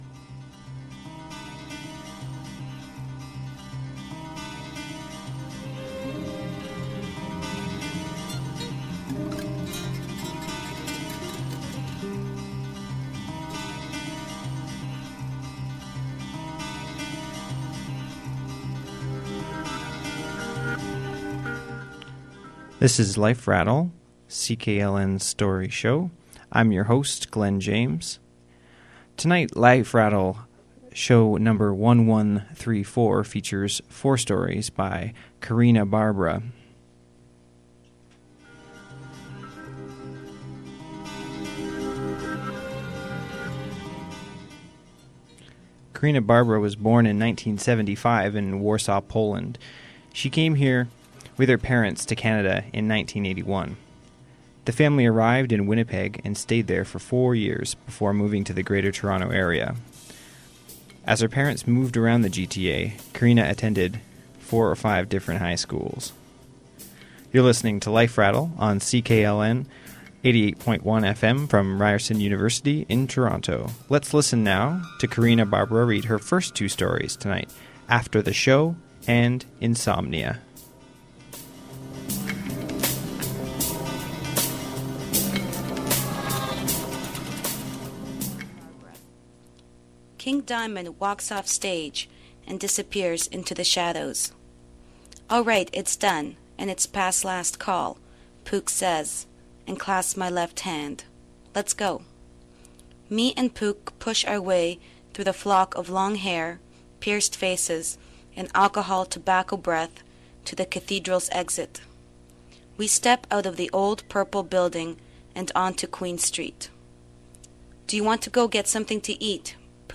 Aired on CKLN FM 88.1 January 9, 2011